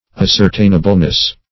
-- As`cer*tain"a*ble*ness , n. -- As`cer*tain"a*bly , adv.